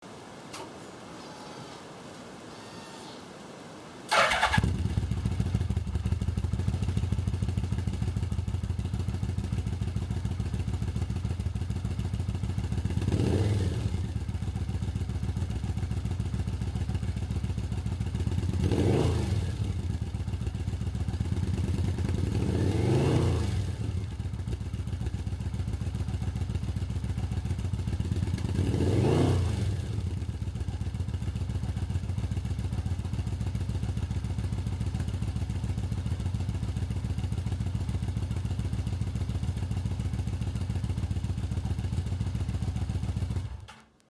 音量は若干大きくなってますが、低音が強調された感じで極端に爆音ではありません。
折角なので約1m後方よりデジカメの動画モードで録音。
ワイズギアマフラー音 (mp3 128kbit/sec) サイズ 690k